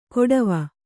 ♪ koḍava